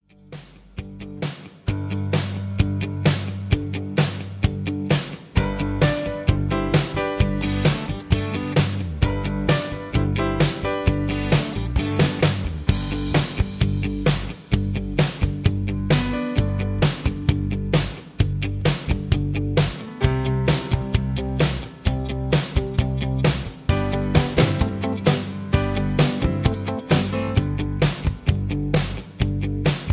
backing tracks, karaoke
country